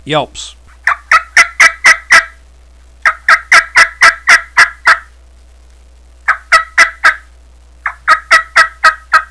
yythreetwoyelps9.wav